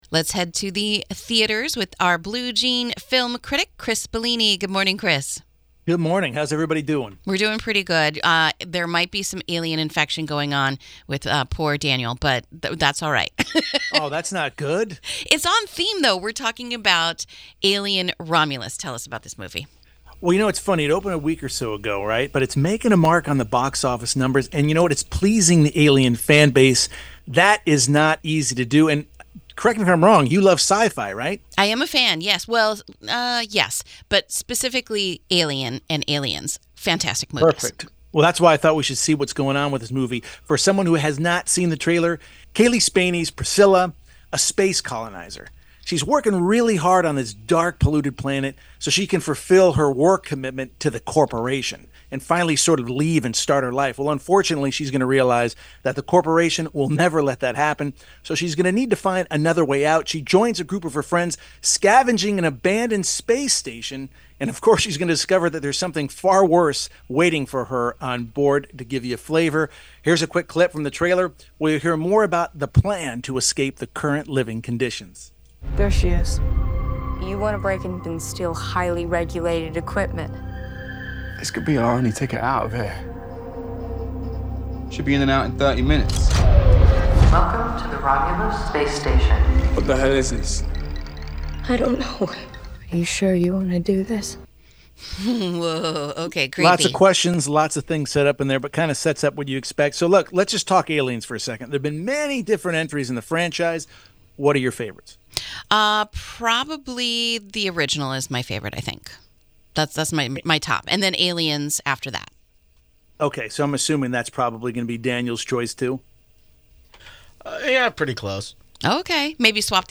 Blue Jean Movie Review of “Alien: Romulus”